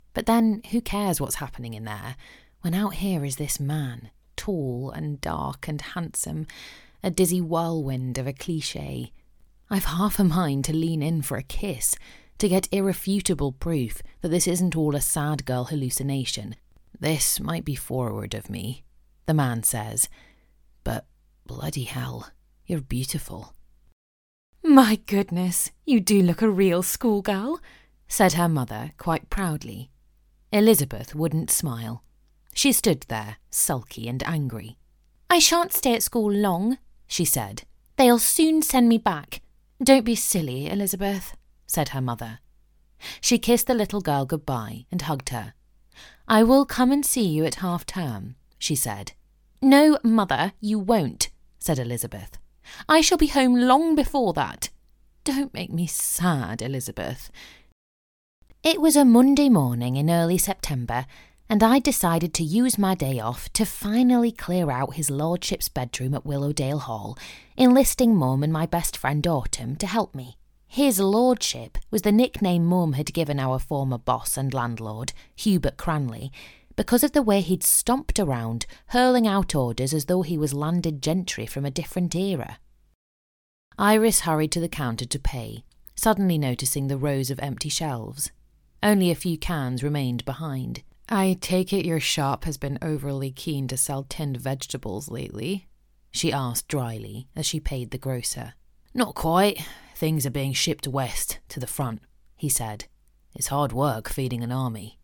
Audiobook Reel
• Native Accent: RP
• Home Studio